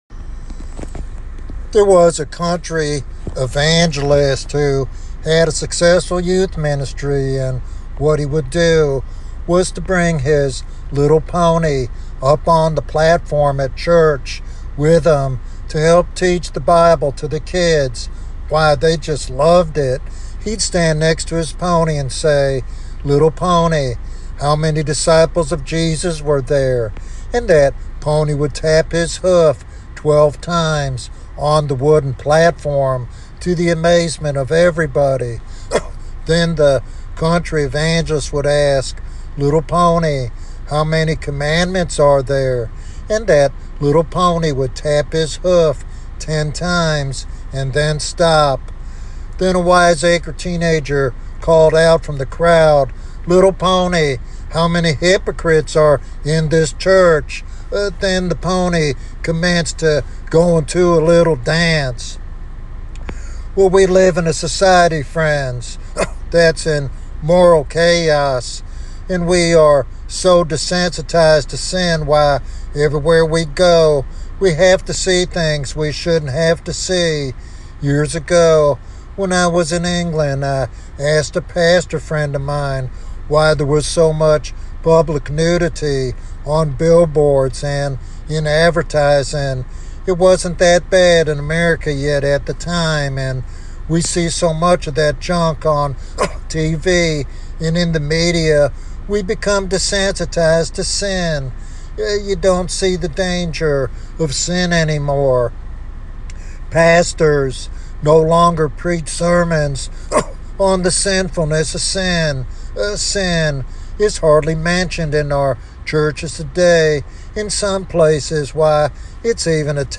This sermon calls listeners to a deeper commitment to holiness and faith, reminding them that true salvation requires a personal encounter with the living God.